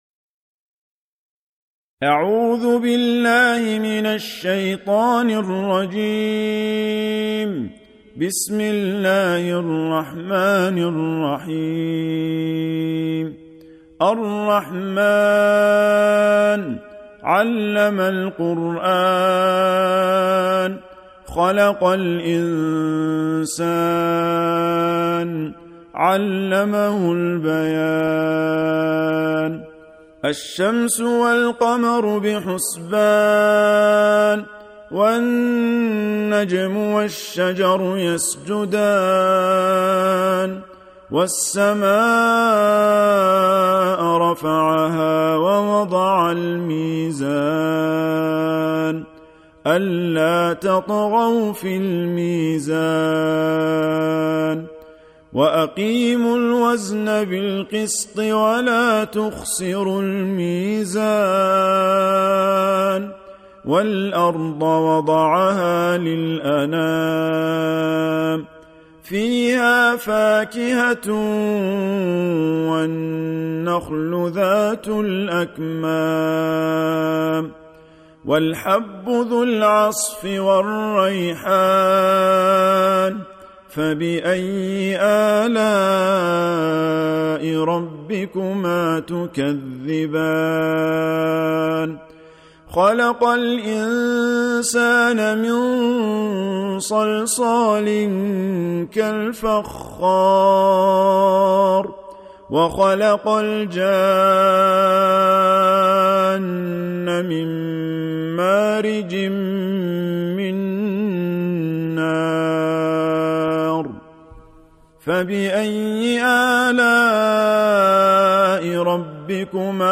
Surah Repeating تكرار السورة Download Surah حمّل السورة Reciting Murattalah Audio for 55. Surah Ar-Rahm�n سورة الرحمن N.B *Surah Includes Al-Basmalah Reciters Sequents تتابع التلاوات Reciters Repeats تكرار التلاوات